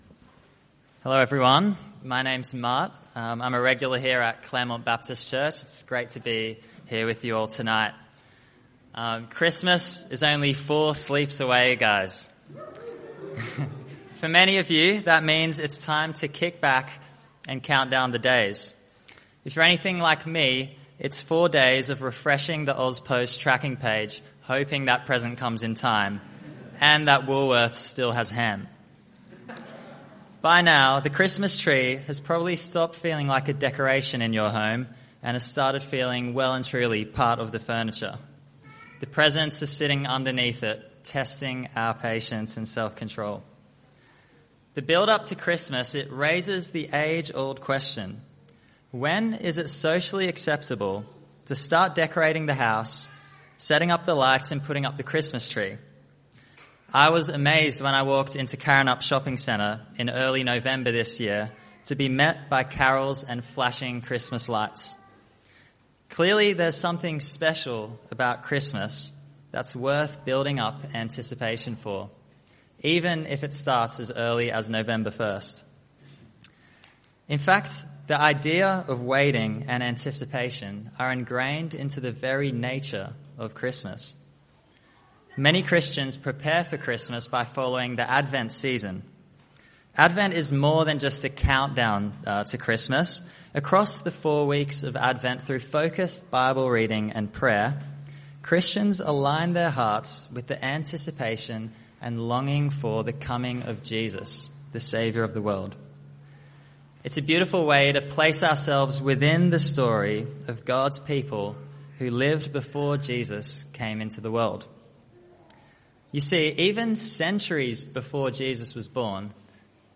Passage: Isaiah 9:6, Isaiah 53:3-6; Isaiah 55:1-3 Type: Sermons